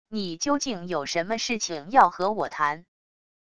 你究竟有什么事情要和我谈wav音频生成系统WAV Audio Player